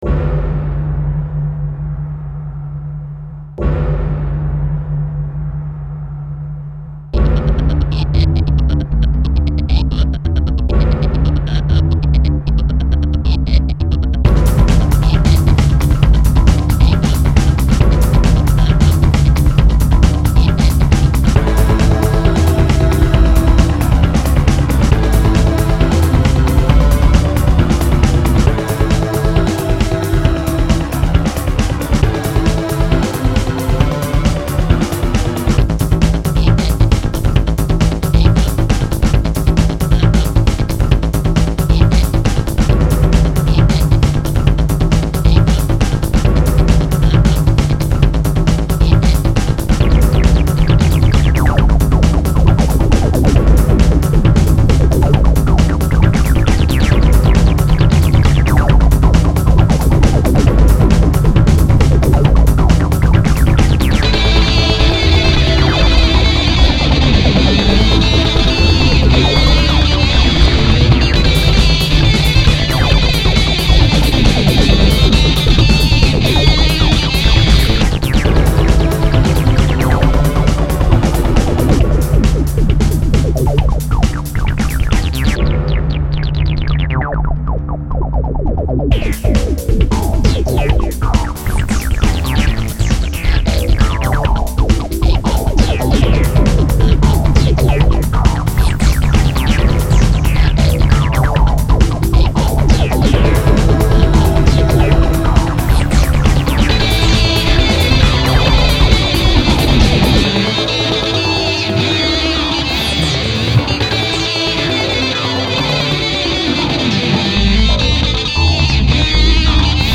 Awesome battle music. Made with Acid Music.